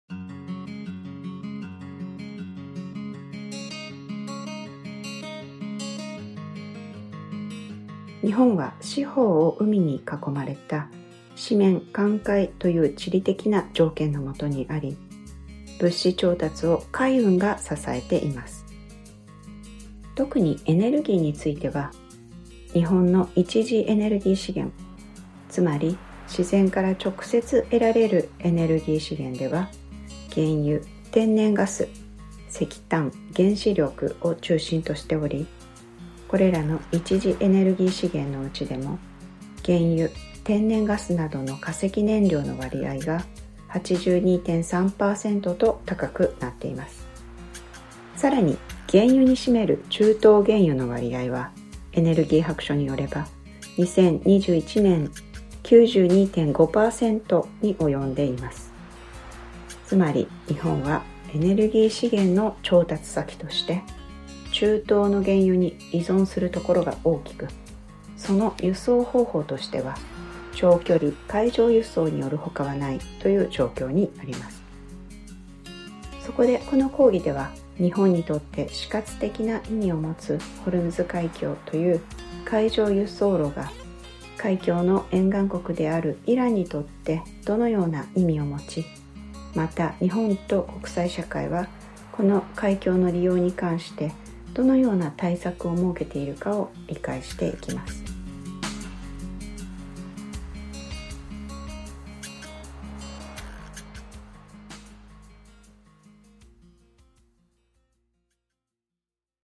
★この講義について (Trailer / Japanese, 1:49″)